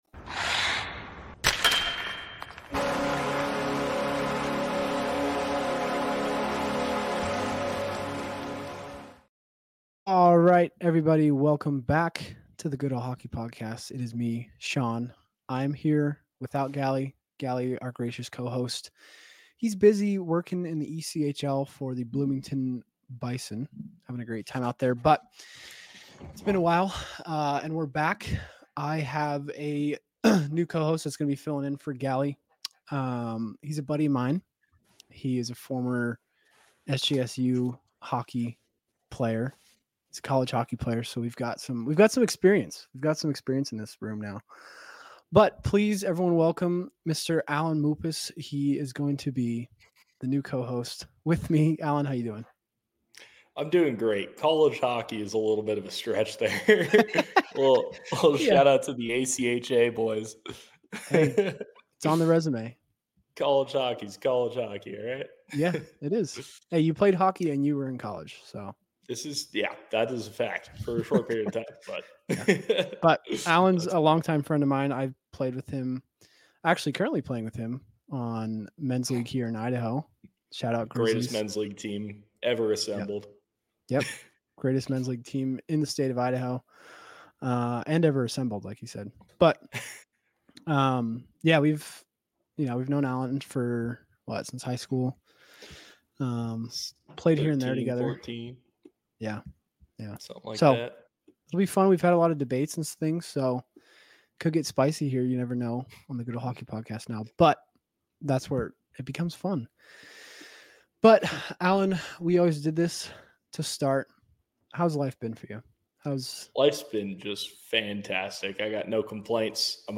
Stay tuned until the end as the duo indulges in a passionate and humorous discussion about their favorite team, the San Jose Sharks.